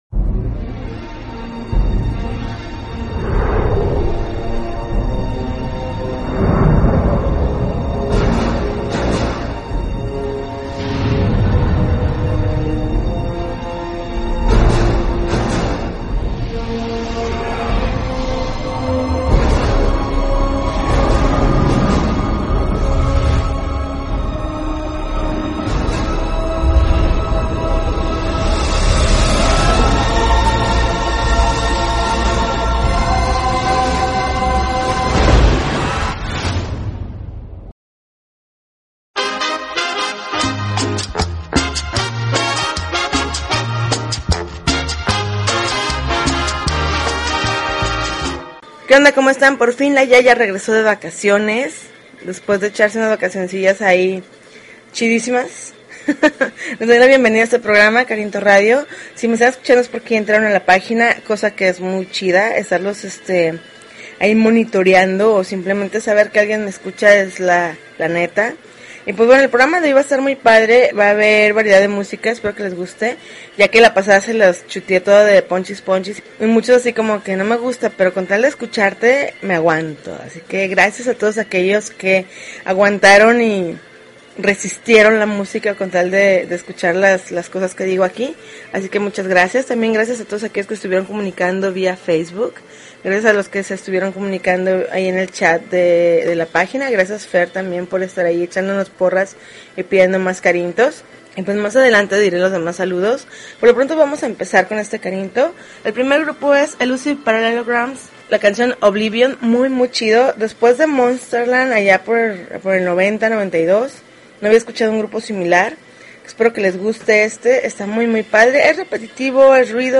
August 26, 2013Podcast, Punk Rock Alternativo